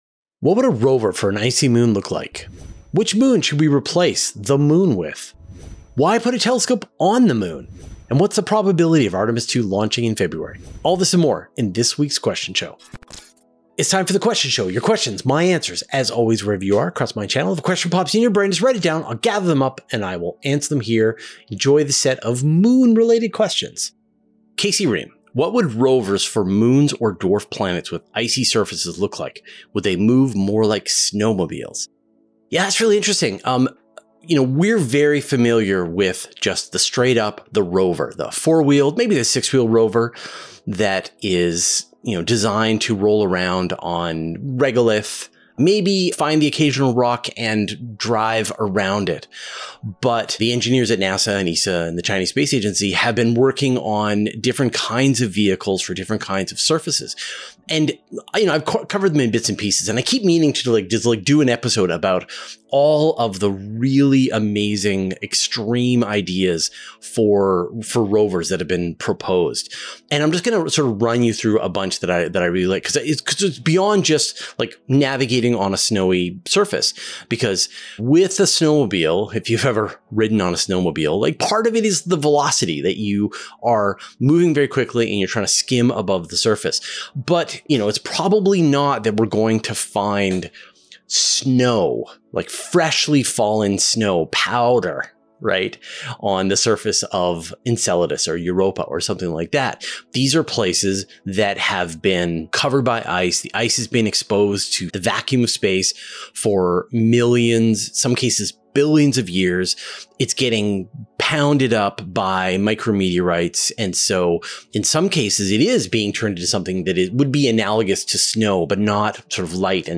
All this and more in this week's Q&A.